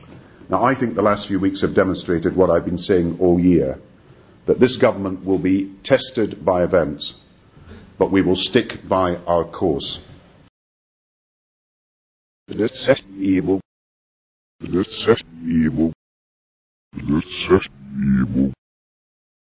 Press Conference with British PM Gordon Brown